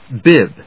/bíb(米国英語)/